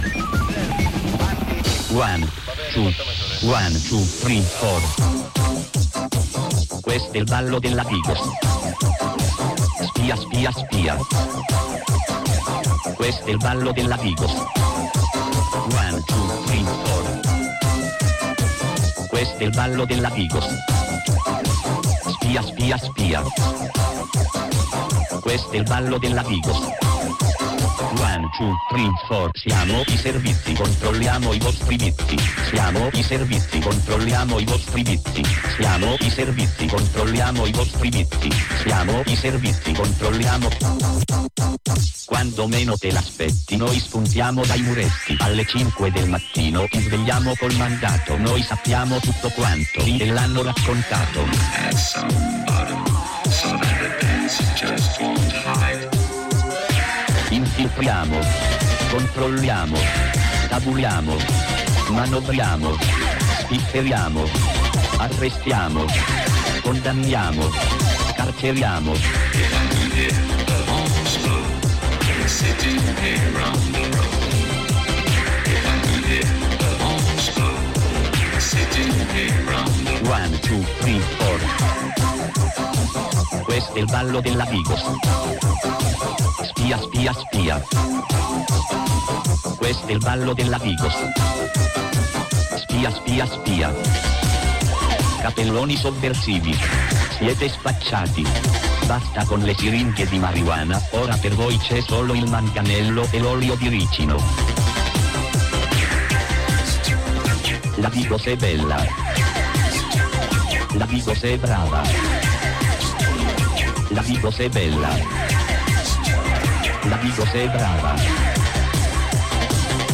In occasione del compleanno del Barocchio, non potevamo che dedicare l’intera puntata ai festeggiamenti dei suoi 30 anni di occupazione. Diverse le dirette che raccontano passato, presente e futuro di questa splendida esperienza, storie piacevoli e meno di chi questo posto l’ha vissuto e lo vive tutt’ora.